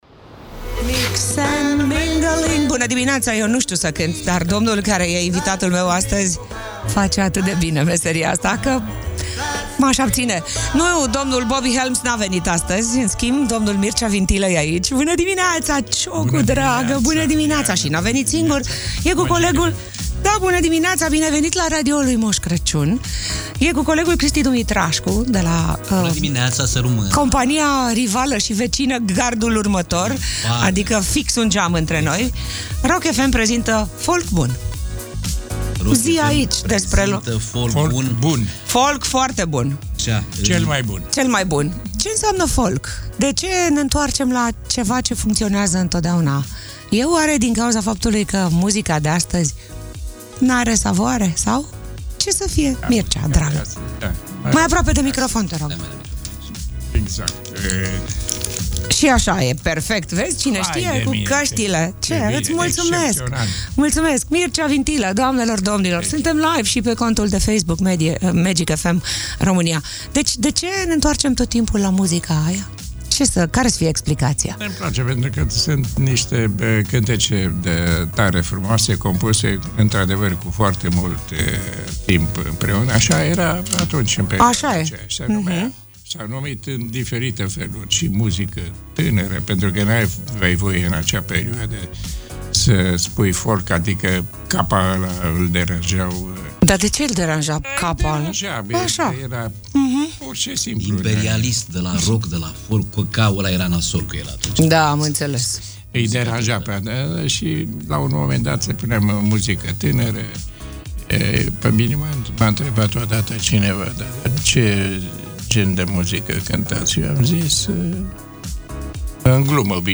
Magic Morning - 18 Decembrie - invitat, Mircea Vintilă LIVE